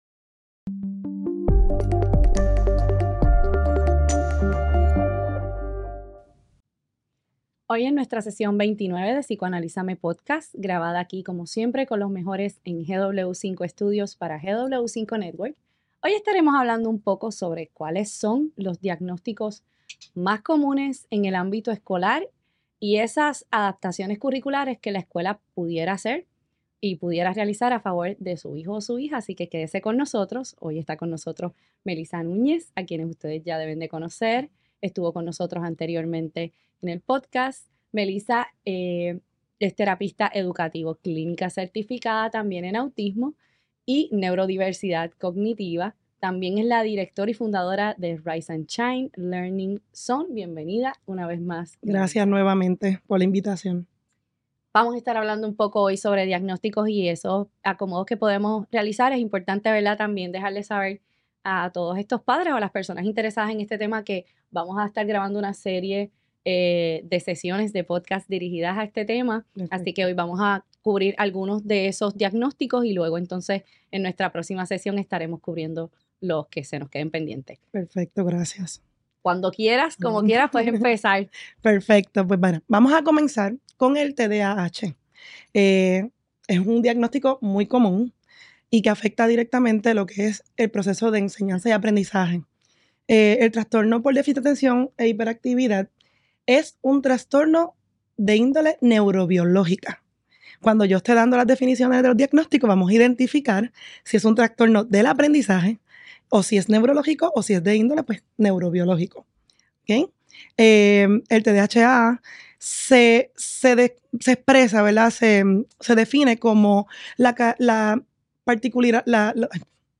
Hoy en nuestra sesión 29 de psicoanalízame podcast grabada aquí como siempre con los mejores GW5 Studios para GW5 Network hoy estaremos hablando un poco sobre cuáles son los Diagnósticos más comunes en el ámbito escolar y esas adaptaciones curriculares que la escuela puede rea...